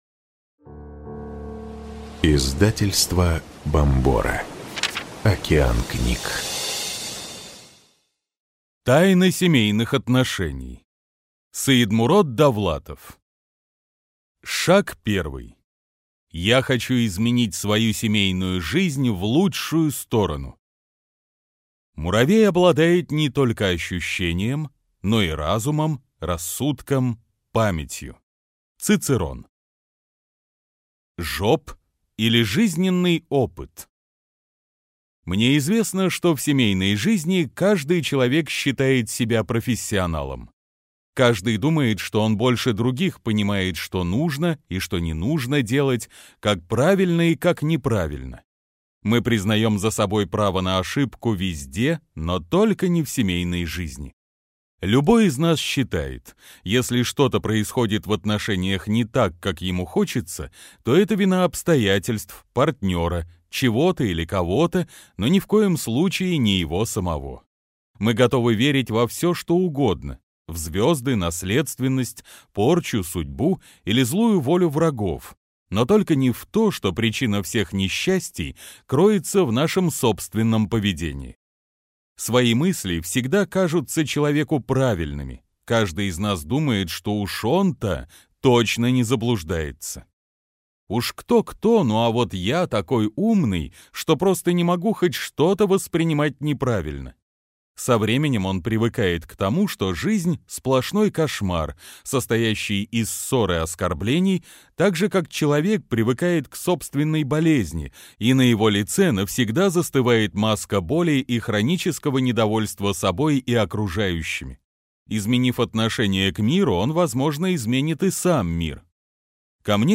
Аудиокнига Тайна семейных отношений. 7 шагов к благополучию | Библиотека аудиокниг